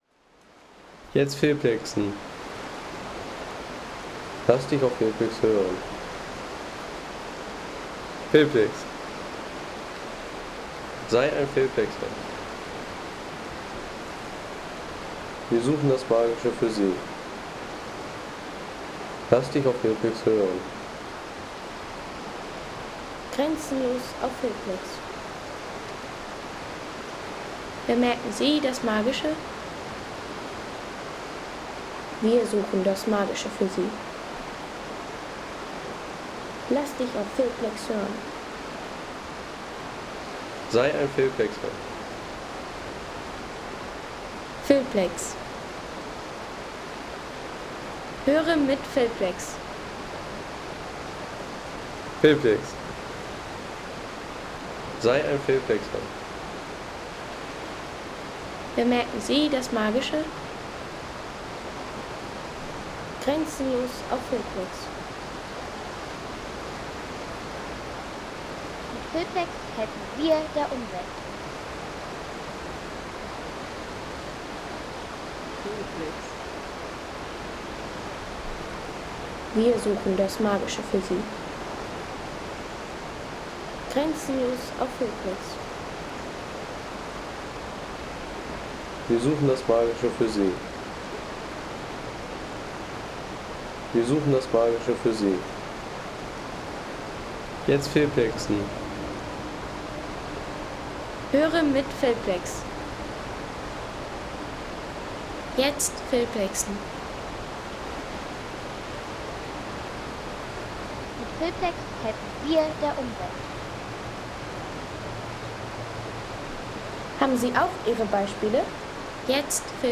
Leierweg Lookout – Enns River & Birds from Gesäuse Viewpoint
Authentic nature recording from the Leierweg in Gesäuse – flowing river, birdsong, and breathtaking alpine atmosphere.
From Weidendom along the Leierweg Trail: This sound recording captures the gentle river Enns, birdcalls, and the calm at one of Gesäuse National Park’s most scenic viewpoints.